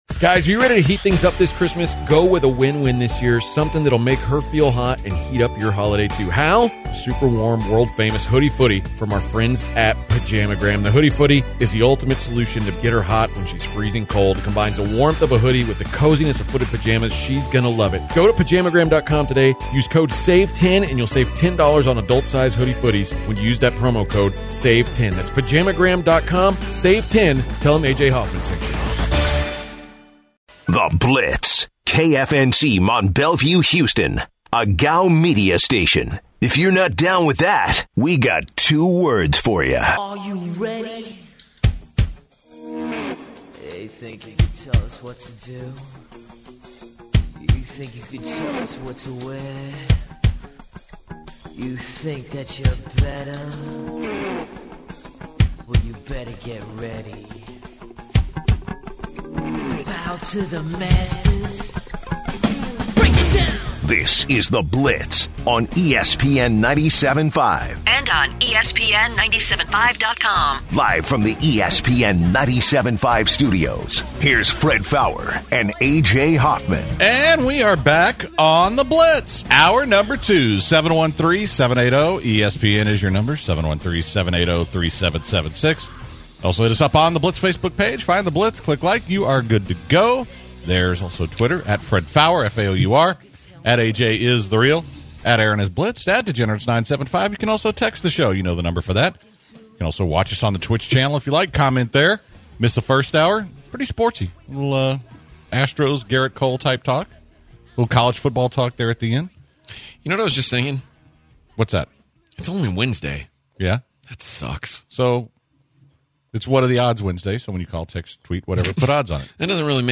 Headliner Embed Embed code See more options Share Facebook X Subscribe The guys start the second hour of the show today discussing their celebrity crushes and which female celebrities are overrated. Then UFC Featherweight Champion Max Holloway joins the show ahead of his fight against Alexander Volkanovski at UFC 245.